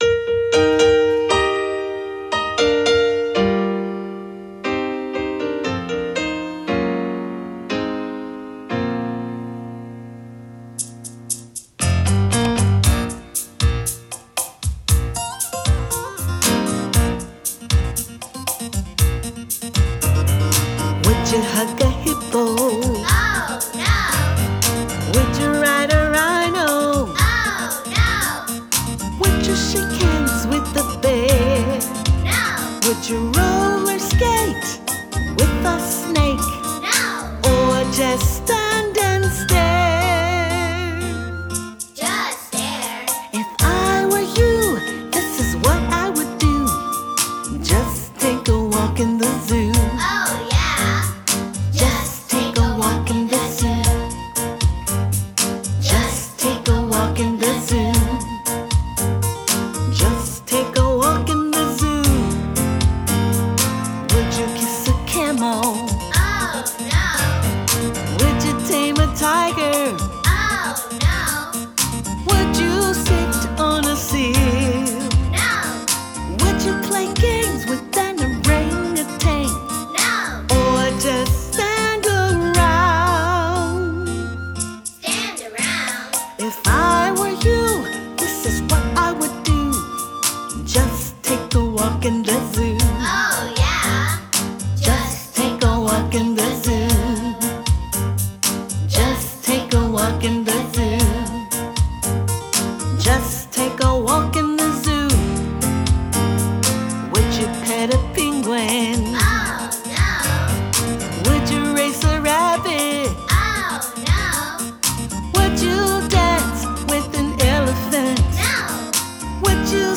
sing-along song